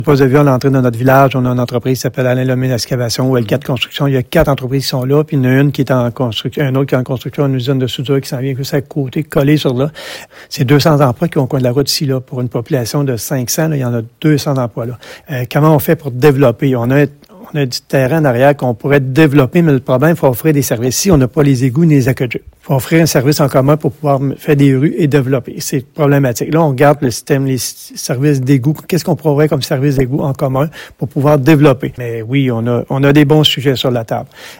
C’est ce qu’ait souligné le maire, Mario Lyonnais.